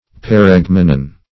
Search Result for " paregmenon" : Wordnet 3.0 NOUN (1) 1. juxtaposing words having a common derivation (as in `sense and sensibility') ; The Collaborative International Dictionary of English v.0.48: paregmenon \pa*reg"me*non`\ n. (Rhet.)